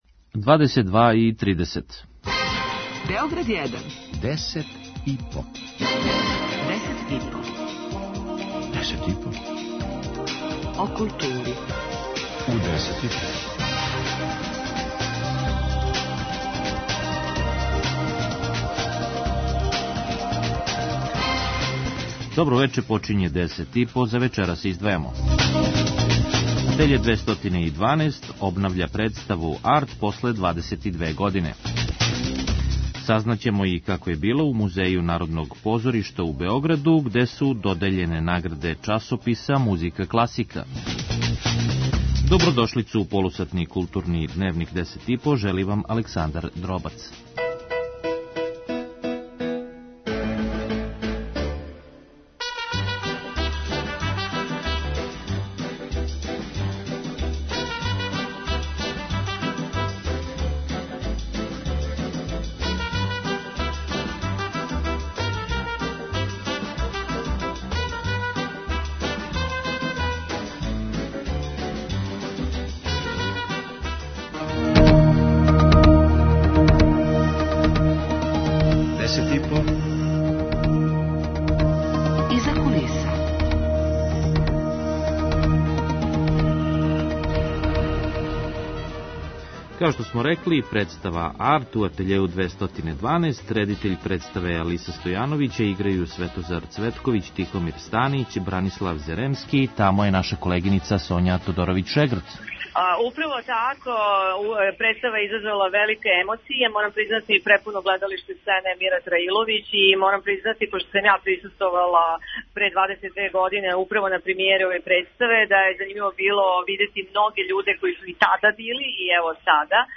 преузми : 5.34 MB Десет и по Autor: Тим аутора Дневни информативни магазин из културе и уметности.